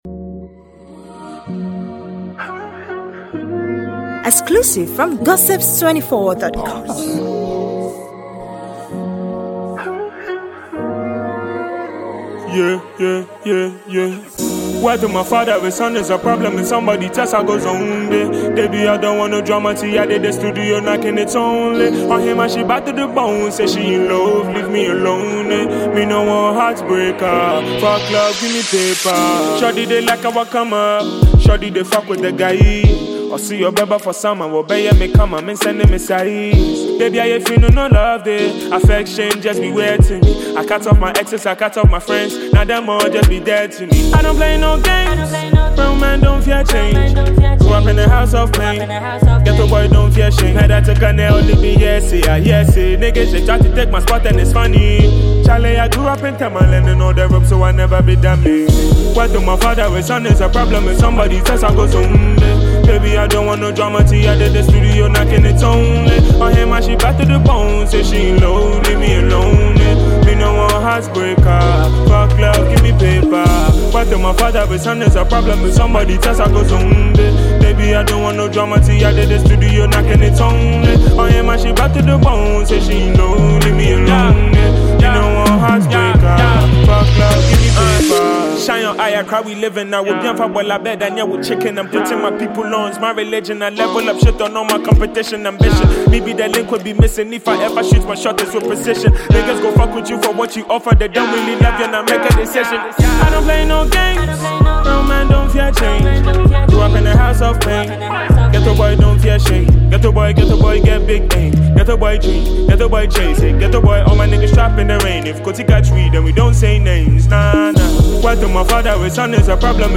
a hip hop tune which will please your ears.